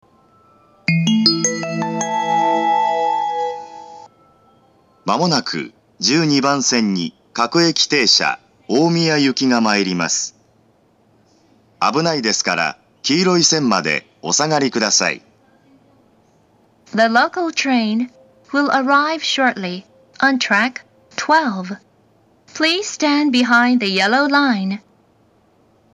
１２番線接近放送
発車メロディー（春ＮｅｗＶｅｒ）
９番線ほどではありませんが、こちらもなかなか鳴りません。